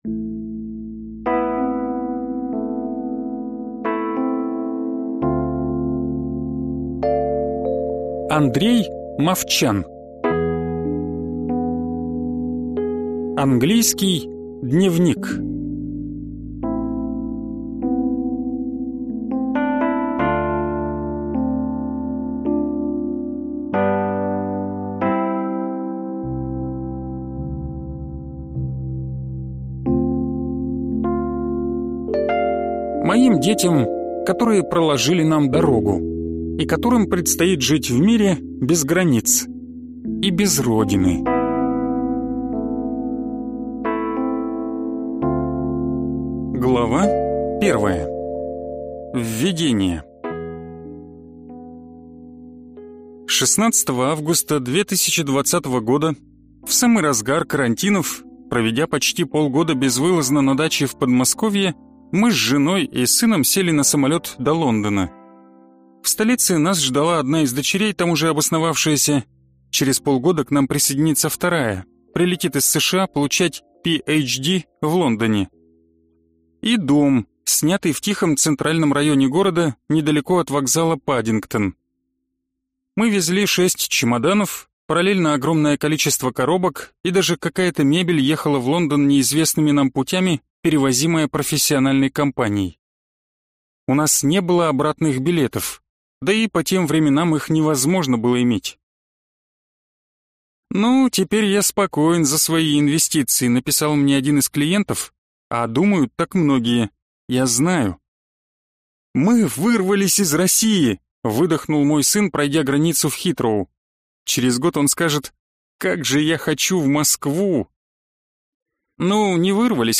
Аудиокнига Английский дневник | Библиотека аудиокниг